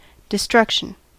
Ääntäminen
IPA : /ˌdɪsˈtɹʌkʃən/